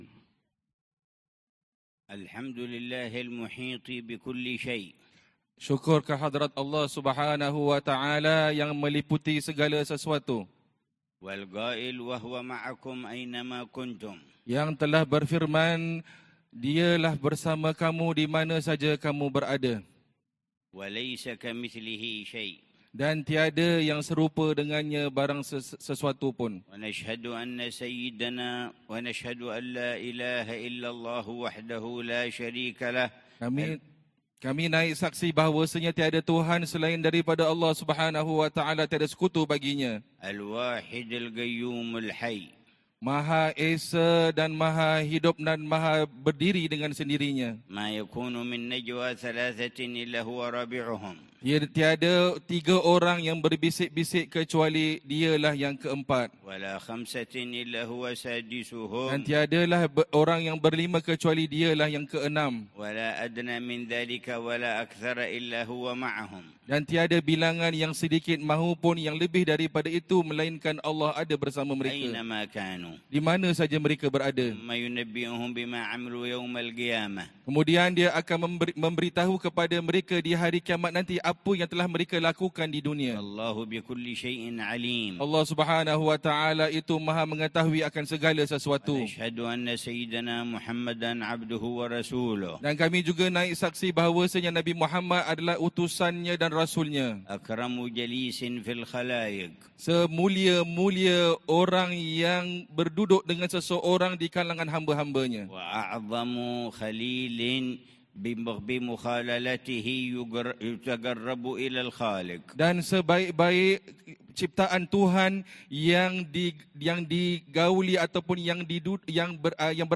كلمة العلامة الحبيب عمر بن حفيظ في مسجد سلطان، سنغافورة، ظهر الجمعة 25 ربيع الثاني 1447هـ بعنوان: المرء على دين خليله